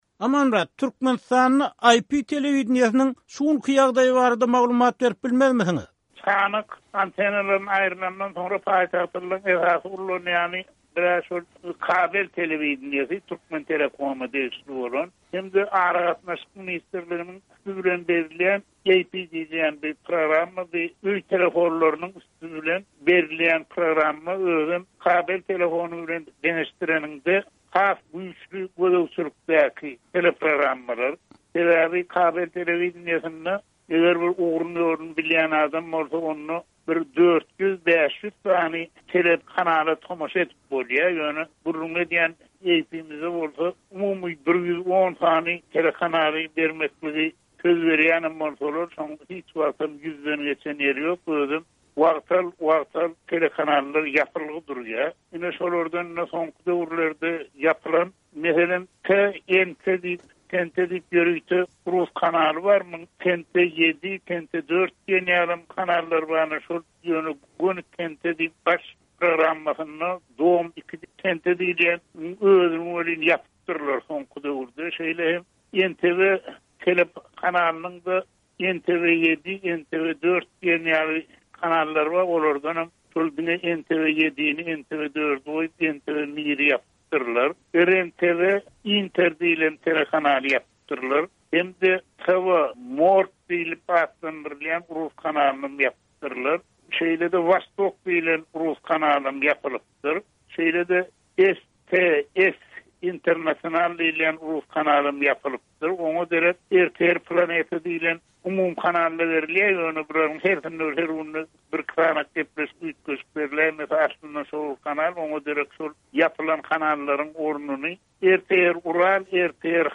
Azatlyk radiosy bu mesele barada ýerli synçy